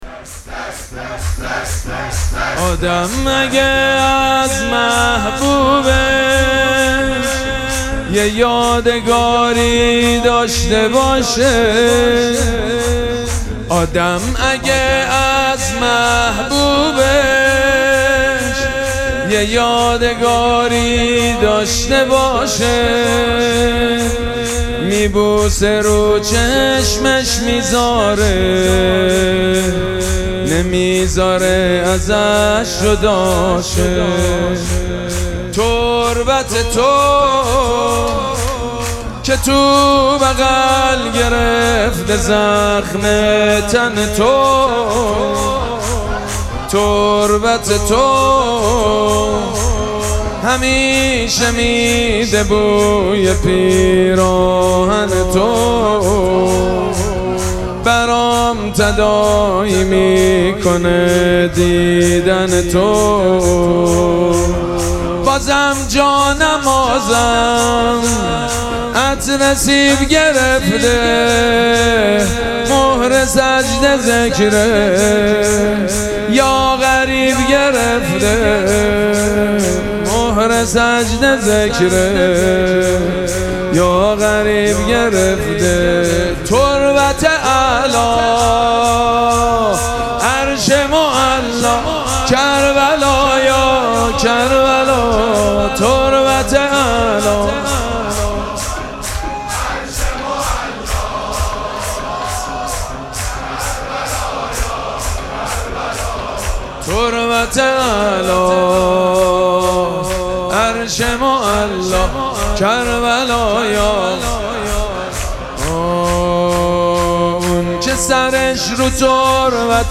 مراسم مناجات شب بیست و سوم ماه مبارک رمضان
شور
مداح
حاج سید مجید بنی فاطمه